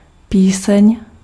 Ääntäminen
France (région parisienne): IPA: [ʃɑ̃]